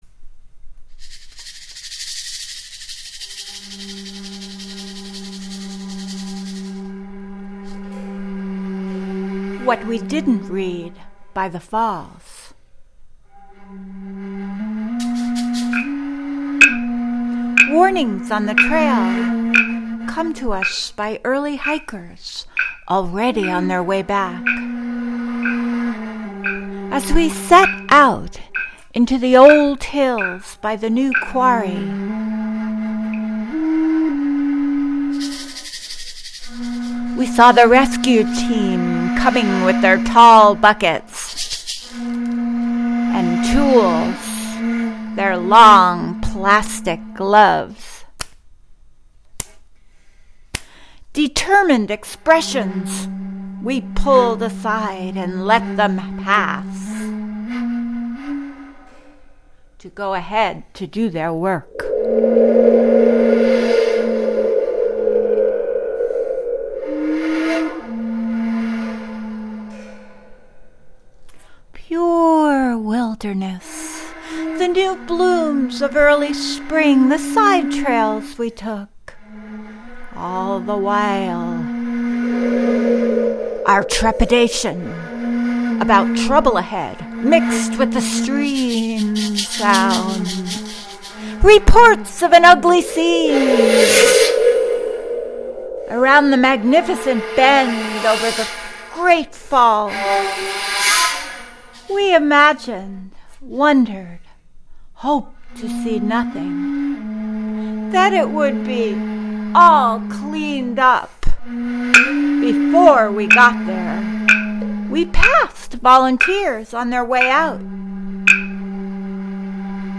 2012 Halloween Poetry Reading
Early Native American Desert Flute
wood block, maraca, tambourine, spring drum, and rubber band